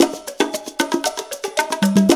CONGA BEAT31.wav